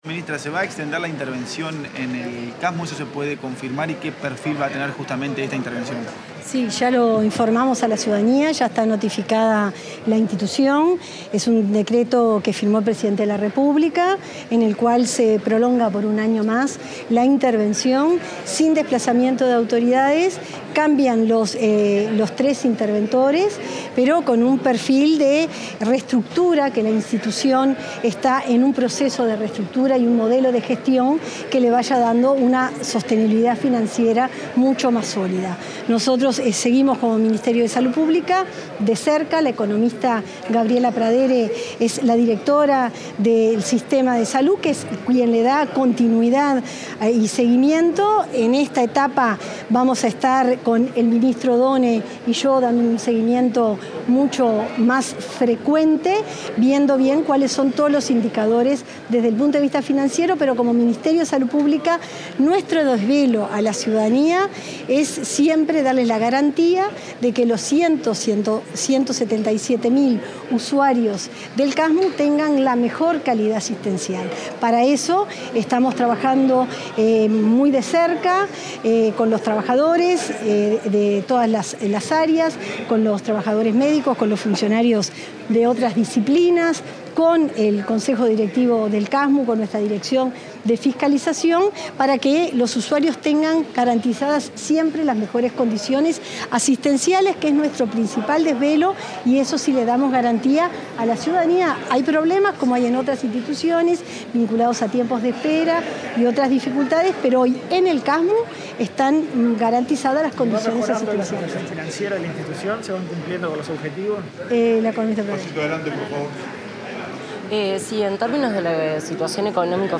Declaraciones de la ministra de Salud Pública, Cristina Lustemberg
Declaraciones de la ministra de Salud Pública, Cristina Lustemberg 30/07/2025 Compartir Facebook X Copiar enlace WhatsApp LinkedIn Tras participar en un encuentro organizado por la Asociación de Dirigentes de Marketing, la ministra de Salud Pública, Cristina Lustemberg, realizó declaraciones a la prensa sobre el alargamiento de la intervención del Casmu.